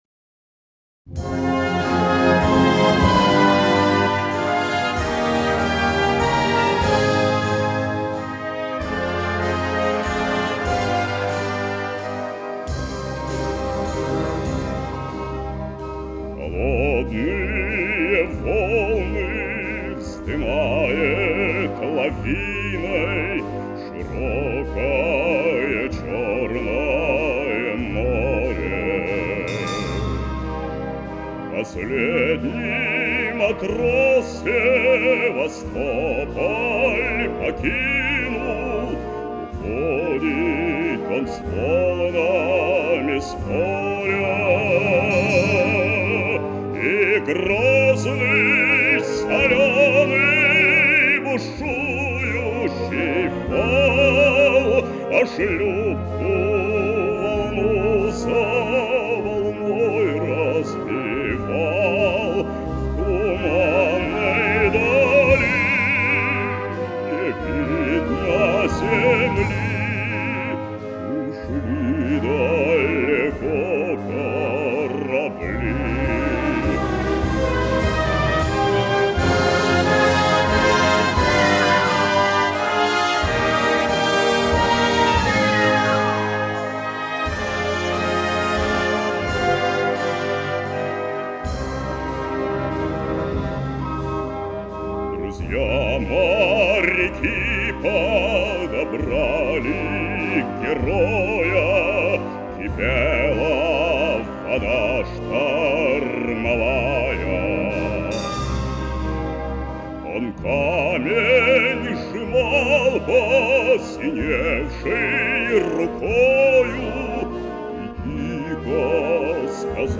Главная / Computer & mobile / Мелодии / Патриотические песни